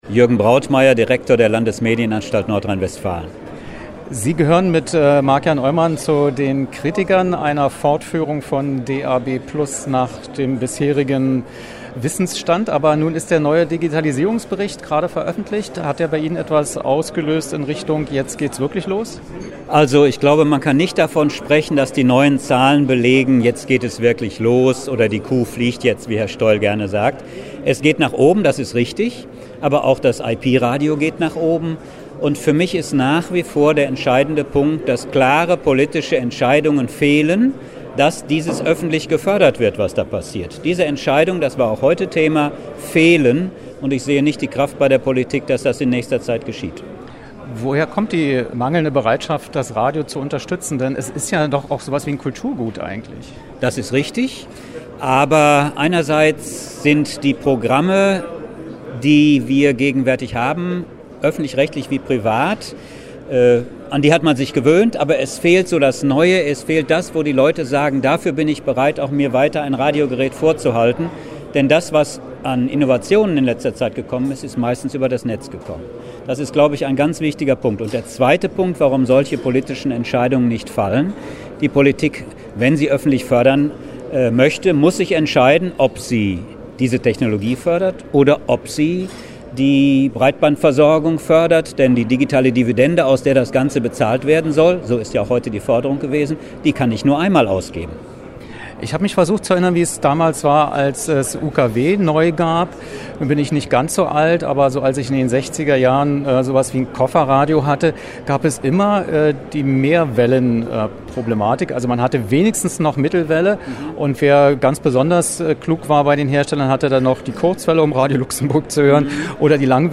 Wer: Dr. Jürgen Brautmeier, Direktor der Landesanstalt für Medien NRW
Was: Interview zu neusten Studien über die DABplus-Nutzung
Wo: Berlin, Messegelände, Marshall-Haus, IFA, Digitalradiotag der Medienanstalten